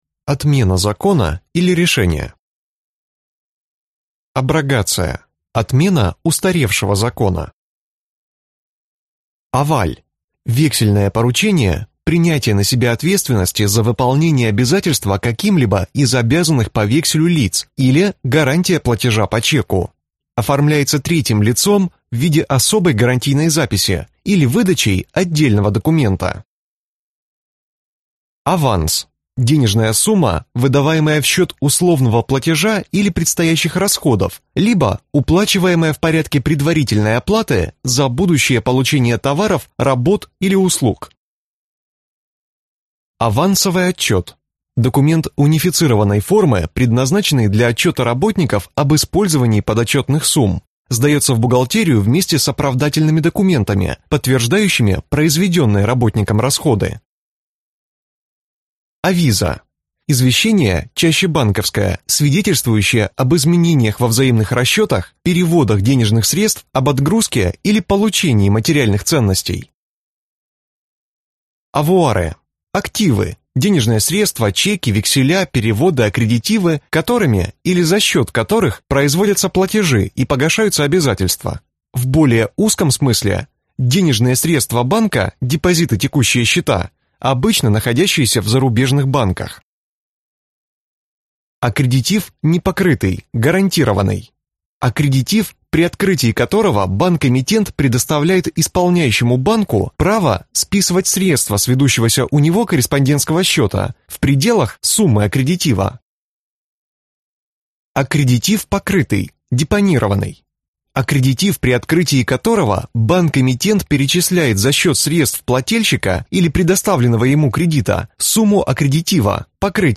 Аудиокнига Словарь бухгалтера | Библиотека аудиокниг